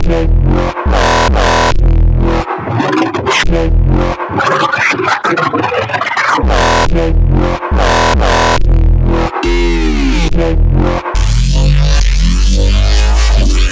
Tag: 140 bpm Dubstep Loops Bass Wobble Loops 2.31 MB wav Key : F FL Studio